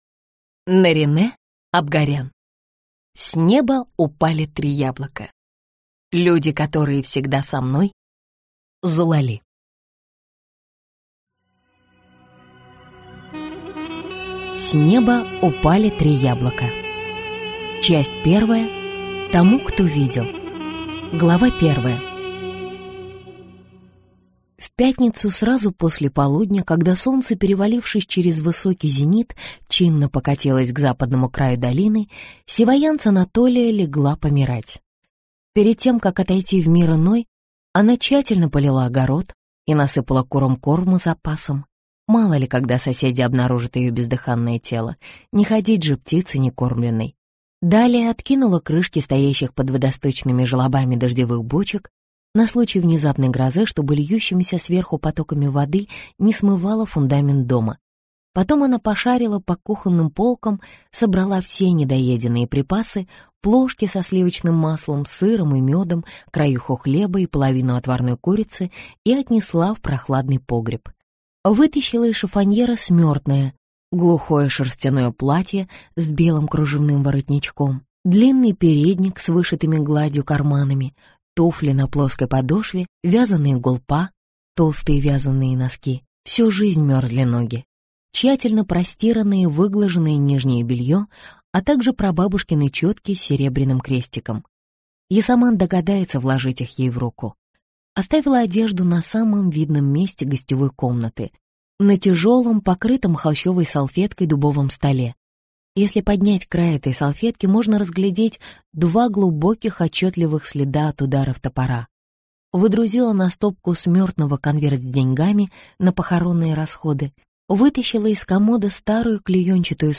Аудиокнига С неба упали три яблока. Люди, которые всегда со мной. Зулали (сборник) | Библиотека аудиокниг